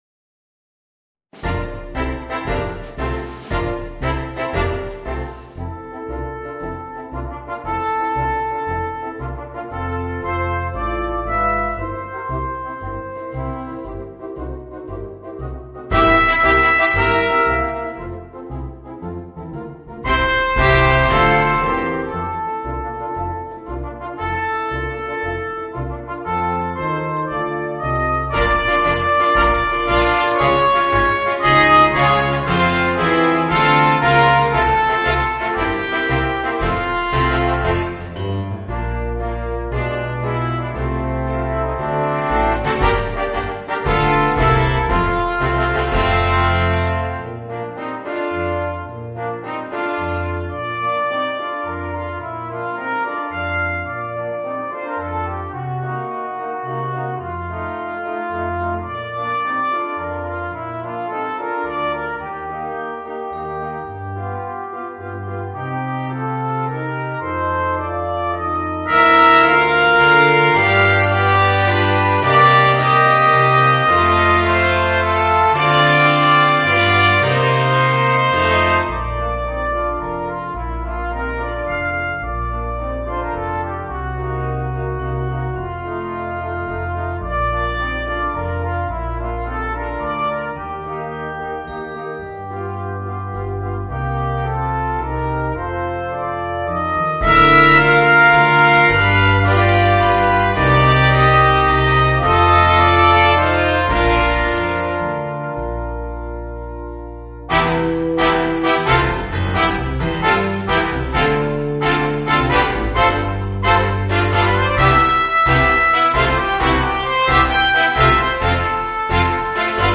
Voicing: 4 - Part Ensemble